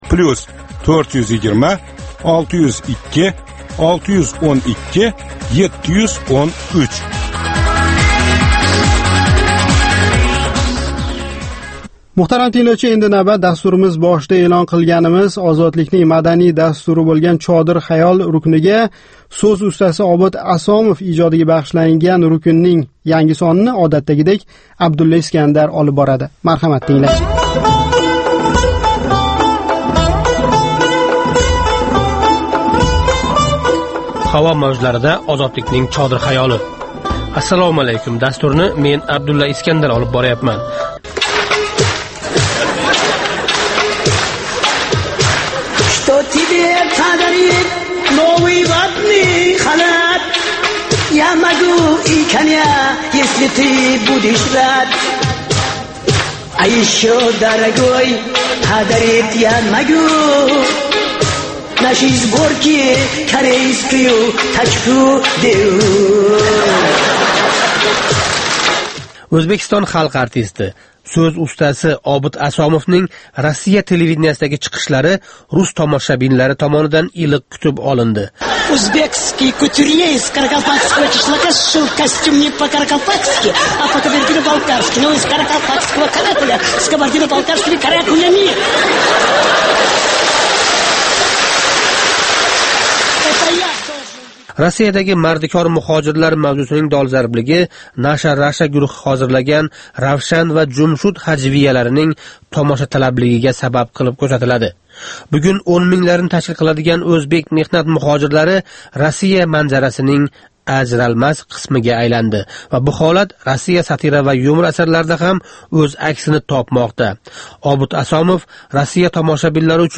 "7 кун - Ўзбекистон": Ҳафта давомида Ўзбекистон сиëсий¸ иқтисодий-ижтимоий ҳаëти¸ қолаверса мамлакатдаги инсон ҳуқуқлари ва демократия вазияти билан боғлиқ долзарб воқеалардан бехабар қолган бўлсангиз "7 кун - Ўзбекистон" ҳафталик радиожурналимизни тинглаб боринг. Бу туркум ҳафтанинг энг муҳим воқеалари калейдоскопидир!